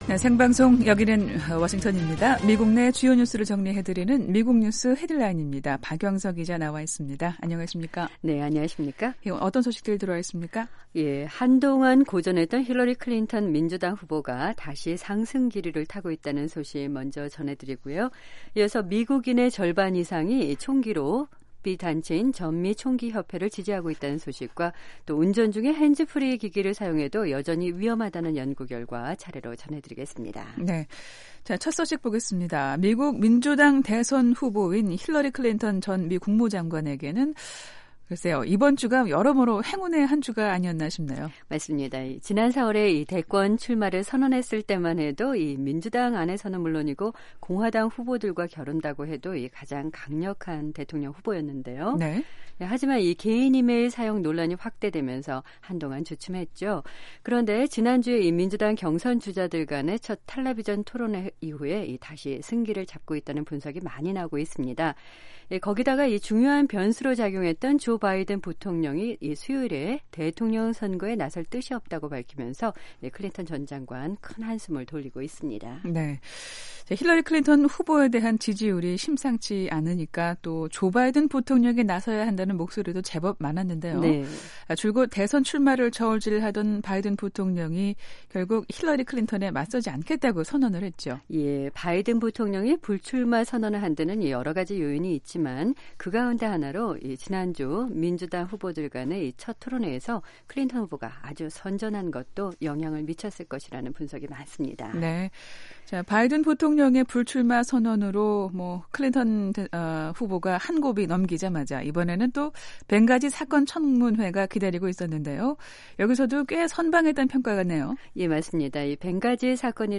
미국 내 주요 뉴스를 정리해 드리는 ‘미국 뉴스 헤드라인’입니다. 한동안 고전했던 힐러리 클린턴 민주당 후보가 다시 상승기류를 타고 있다는 소식 먼저 전해드리고요. 이어서 미국인의 절반 이상이 총기로비단체인 전미총기협회를 지지하고 있다는 소식과 운전 중에 핸즈프리 기기를 사용해도 여전히 위험하다는 연구 결과, 차례로 전해드리겠습니다.